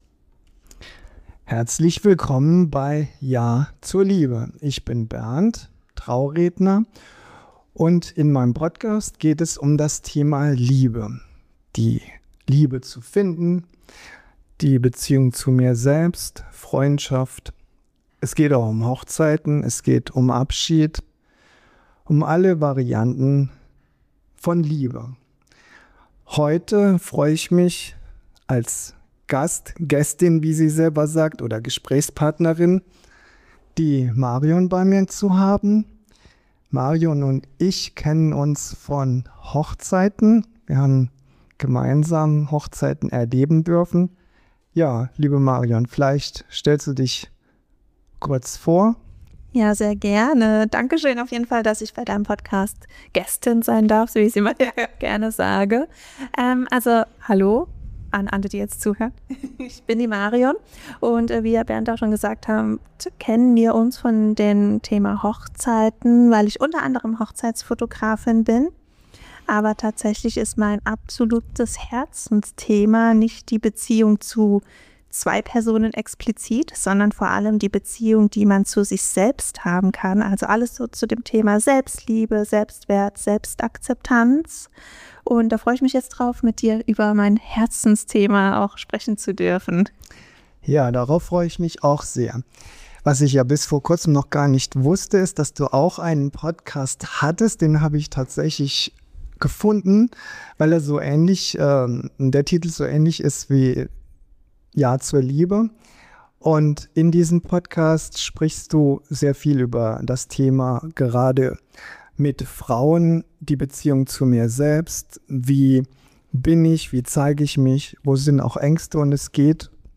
Ja zu sich selbst sagen - Deep Talk